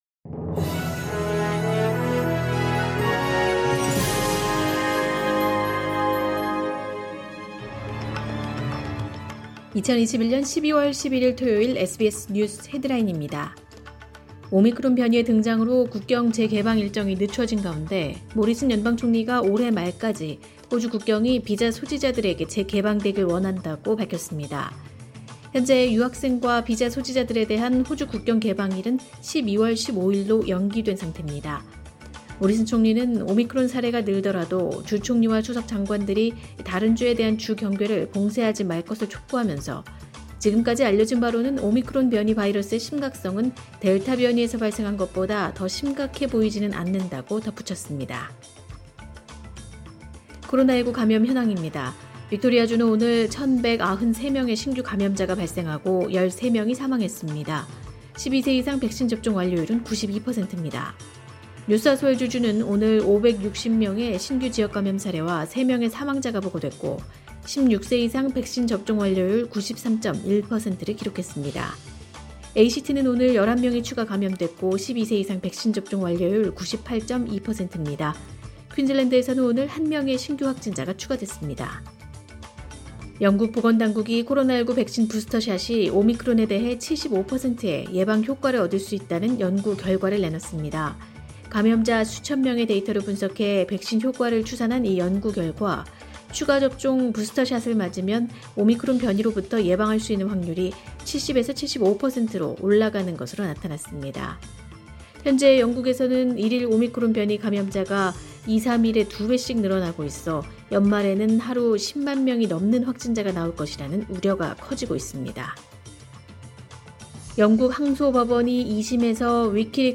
2021년 12월 11일 토요일 SBS 뉴스 헤드라인입니다.